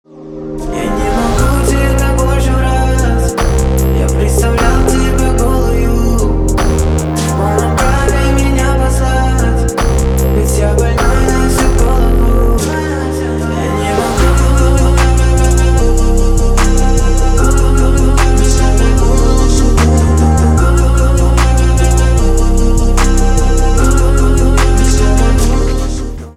поп
битовые , басы , качающие